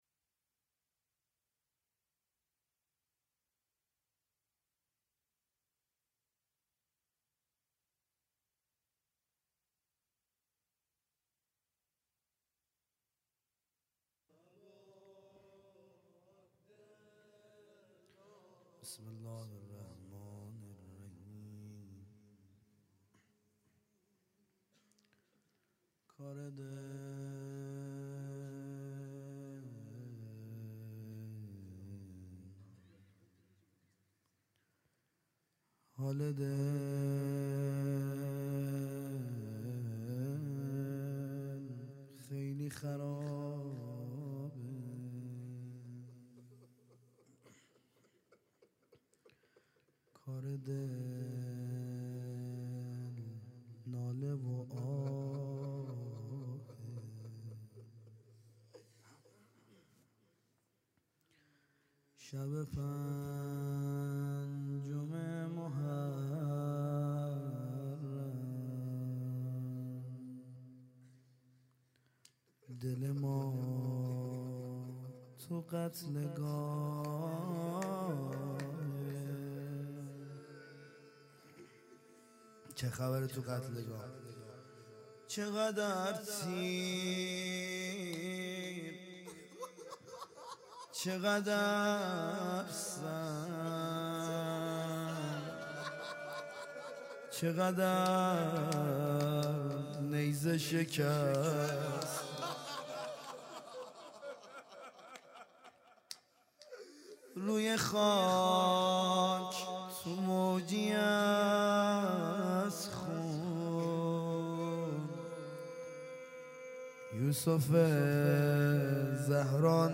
خیمه گاه - هیئت دانشجویی فاطمیون دانشگاه یزد - روضه